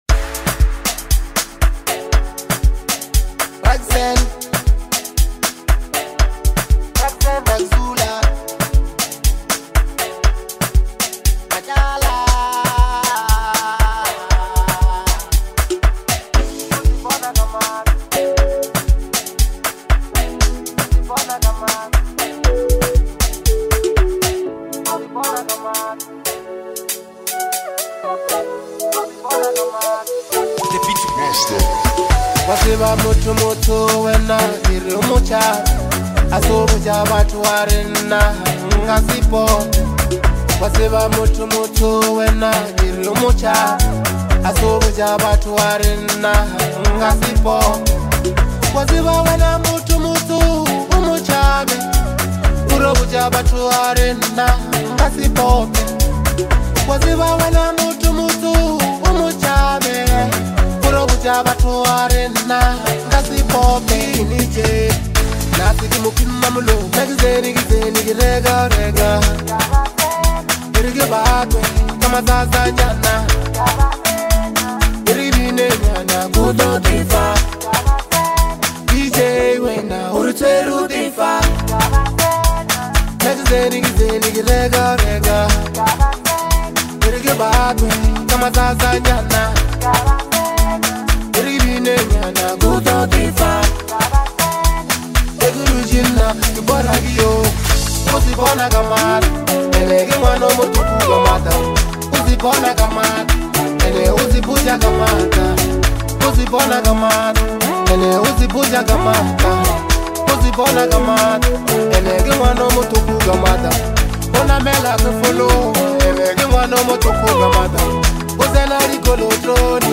Bolohouse